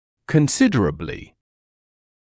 [kən’sɪdərəblɪ][кэн’сидэрэбли]значительно, существенно